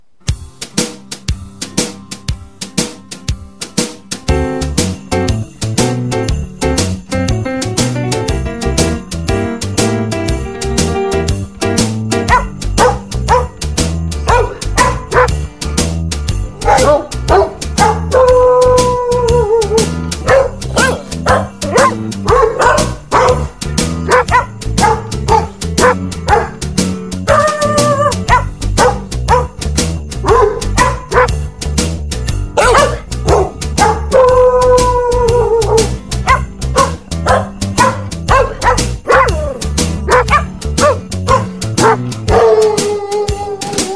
Animals sounds